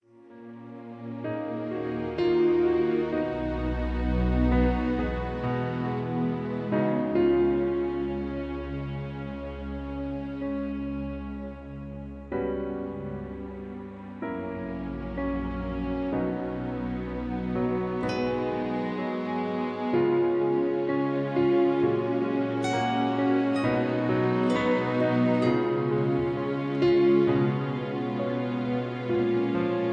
Key-F
Karaoke MP3 Backing Tracks